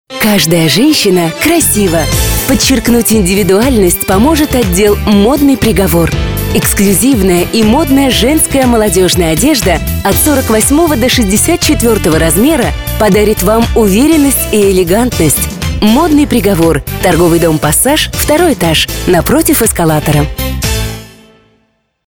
АУДИО-РОЛИК до 30 секунд, 1 день